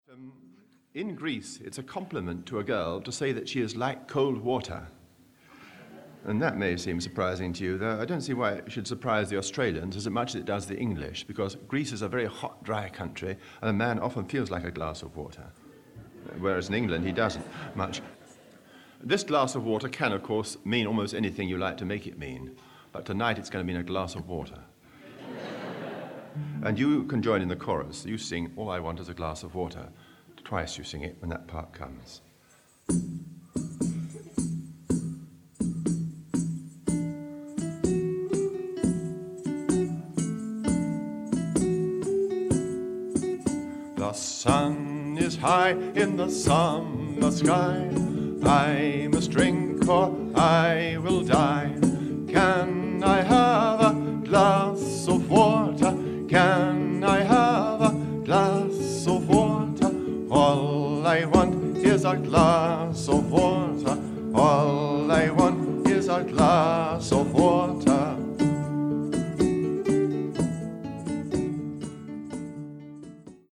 Spoken word, Vocal